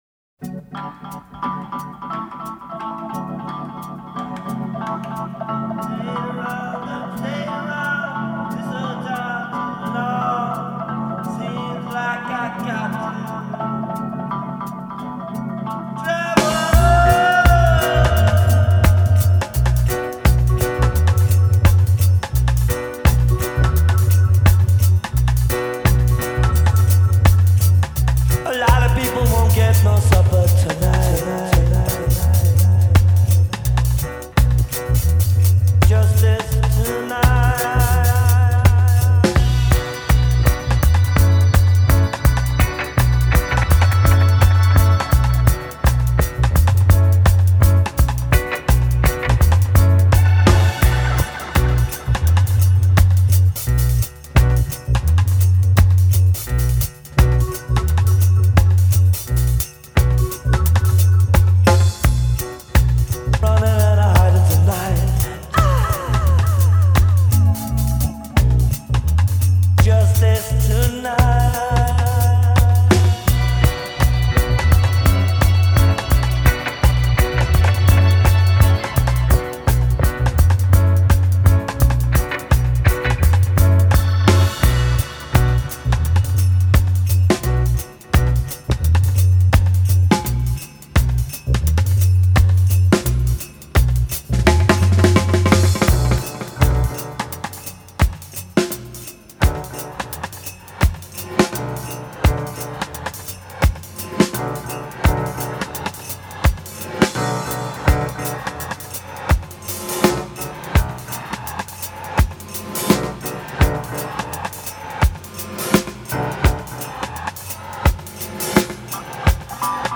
free-form and ad-libbed after the 3 minute mark